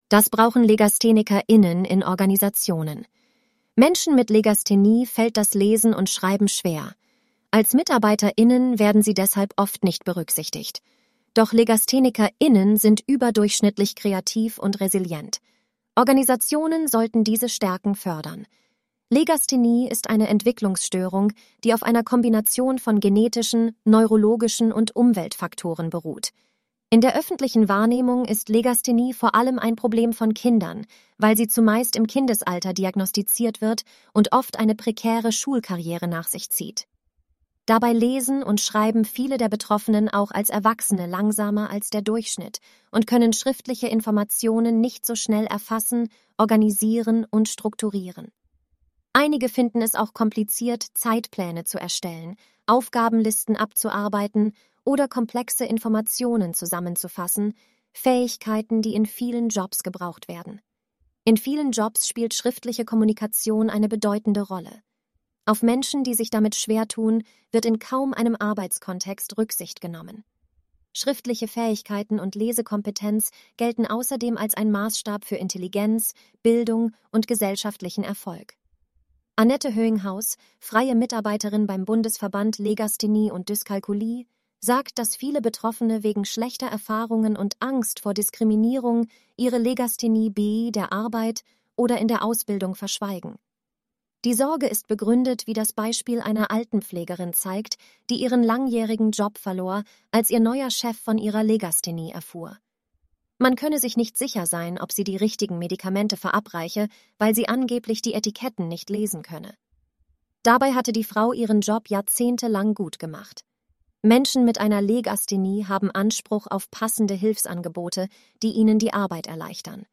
Zum Vertonen haben wir eine künstliche Intelligenz genutzt.